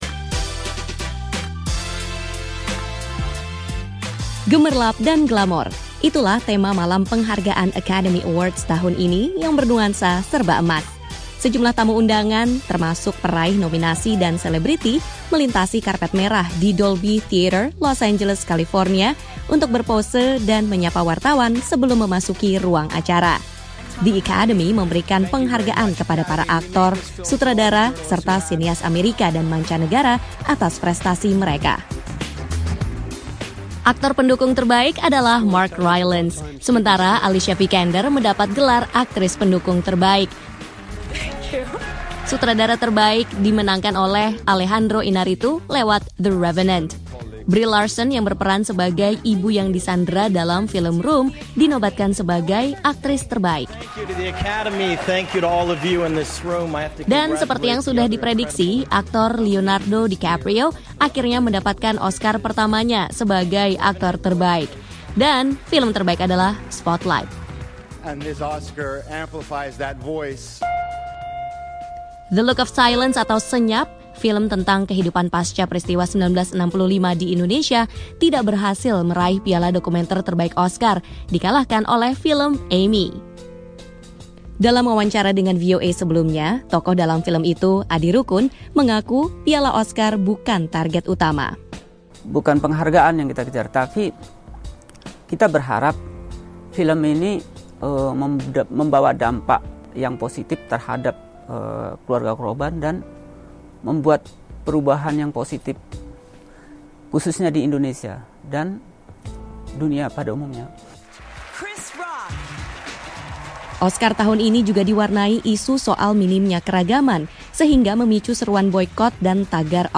Isu keanekaragaman mewarnai perhelatan Academy Awards tahun ini. Lalu siapa saja yang membawa pulang piala Oscar? Simak laporan